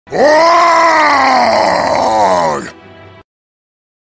flashgitz-waaagh-sound-effect.mp3